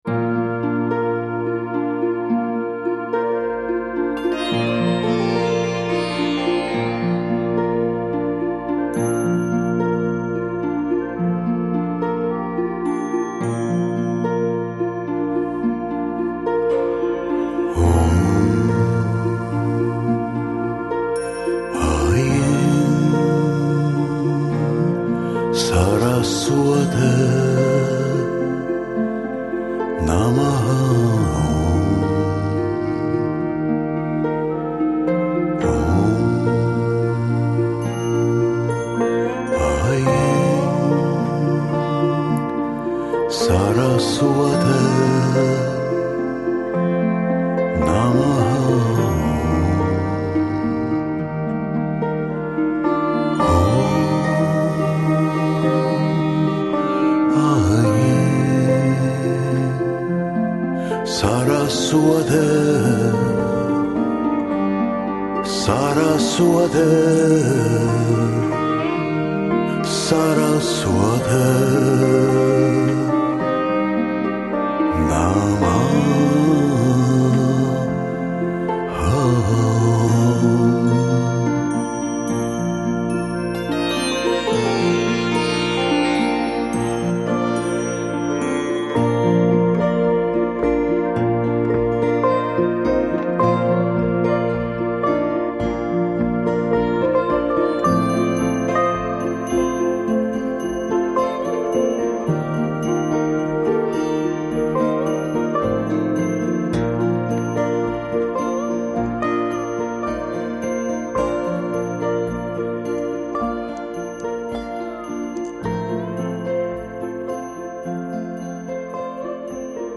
Жанр: Relax, Meditative